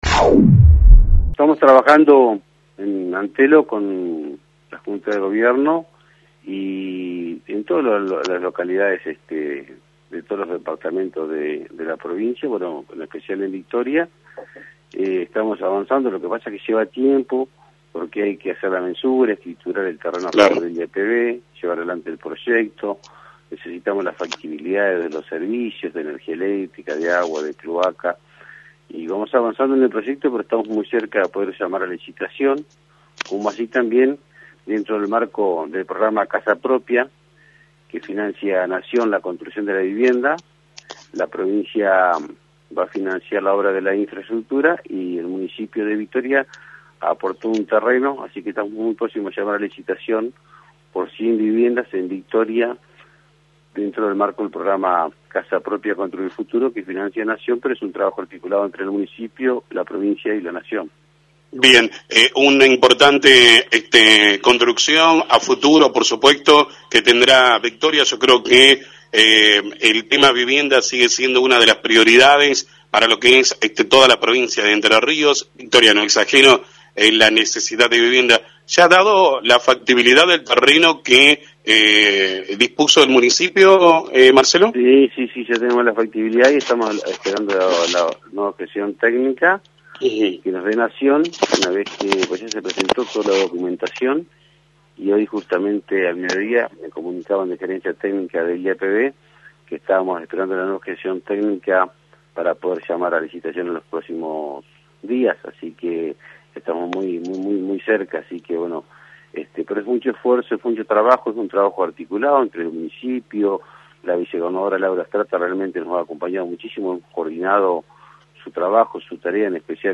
El presidente del Iapv, Marcelo Bisogni confirmó esta tarde en FM 90.3 que se llamara a licitación para la construcción de 100 viviendas en Victoria, en el marco del programa “Casa Propia, Construir Futuro”.
Marcelo Bisogni – Presidente IAPV